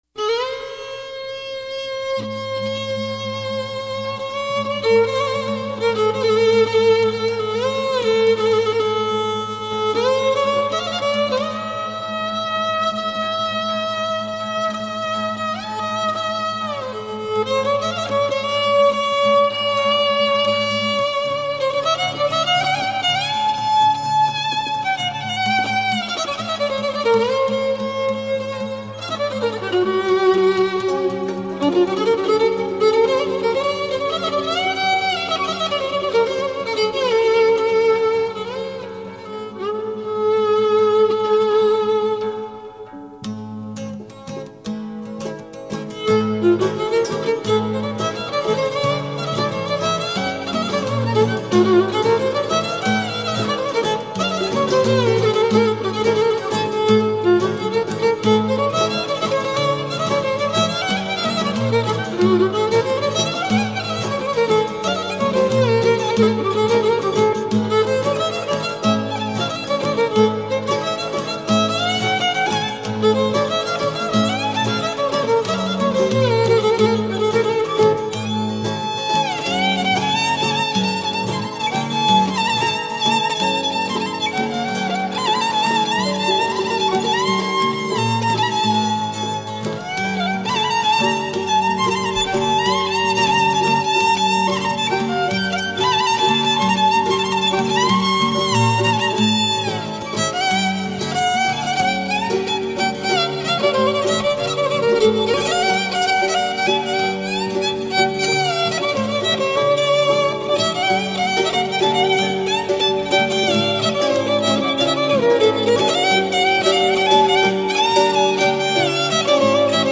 ΤΡΑΓΟΥΔΙΑ ΚΑΙ ΣΚΟΠΟΙ ΑΠΟ ΤΑ ΔΩΔΕΚΑΝΗΣΑ